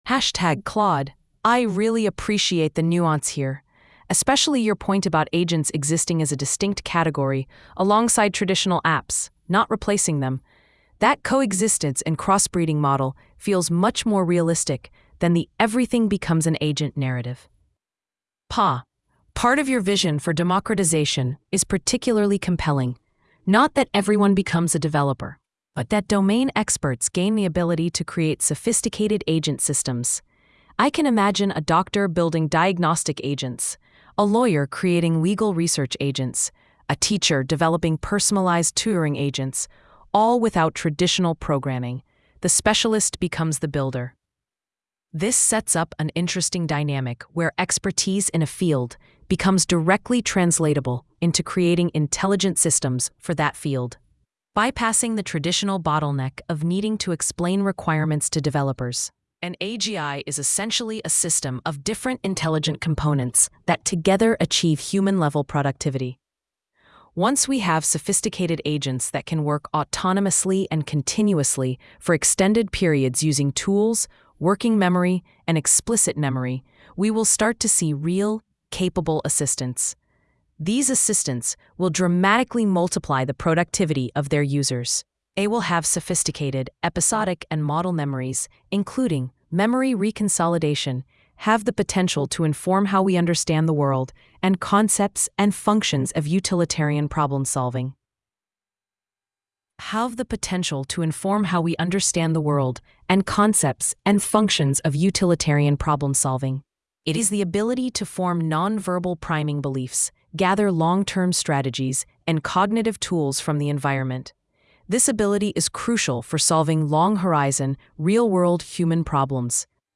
Audio: This post has audio recordings generated using gpt-4o-mini-speech placed at the beginning of each section.